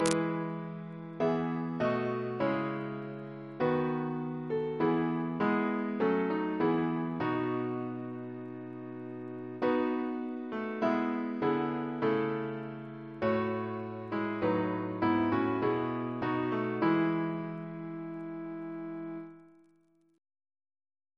Double chant in E Composer: William Russell (1777-1813) Reference psalters: ACB: 18; PP/SNCB: 162; RSCM: 117